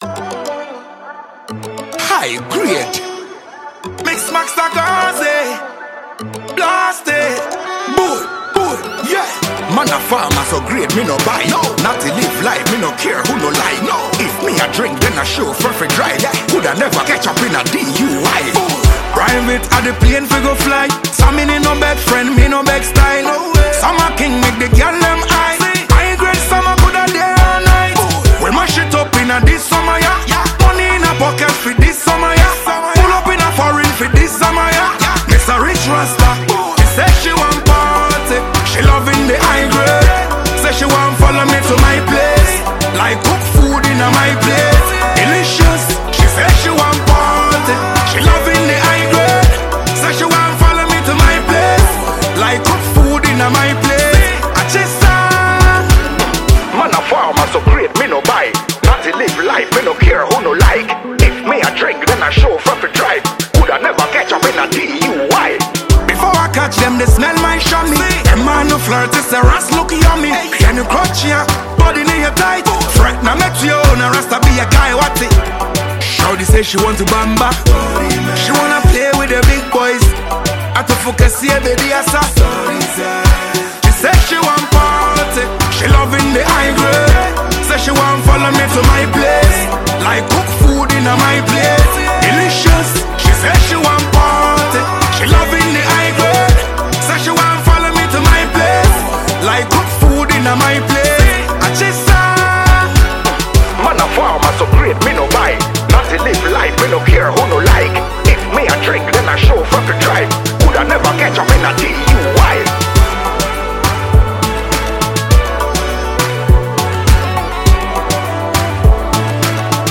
Genre: Reggae/Dancehall